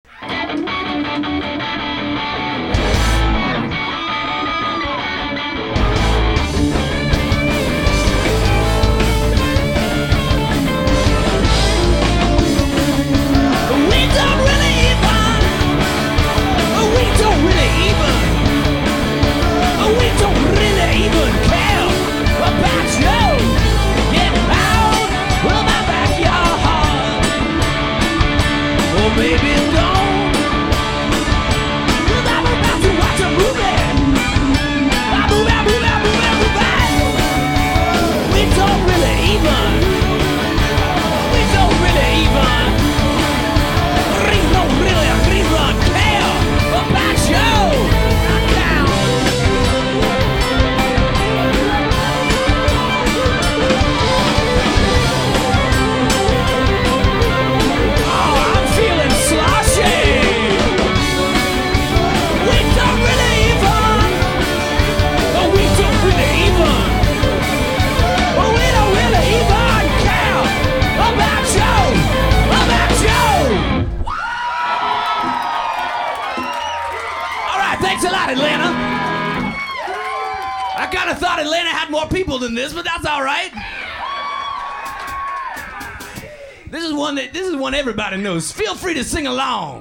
Rockin' out, for reals